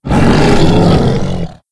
c_sibtiger_dead.wav